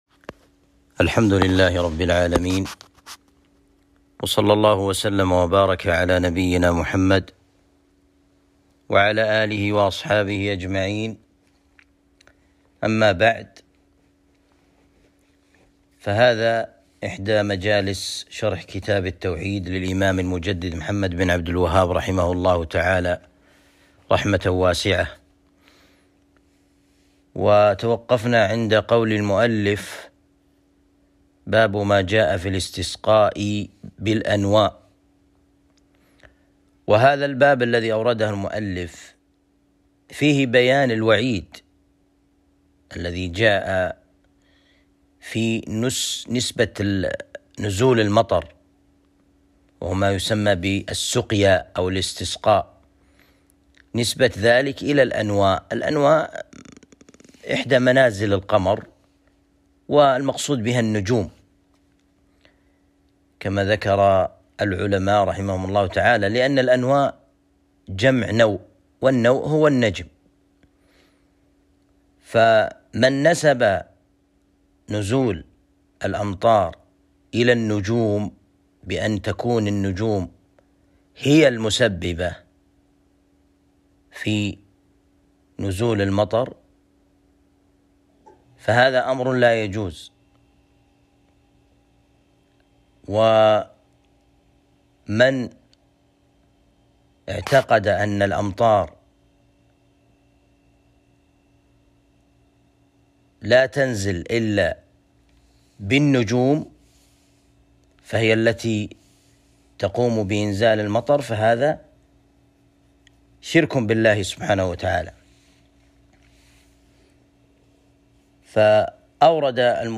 درس شرح كتاب التوحيد (٣٠)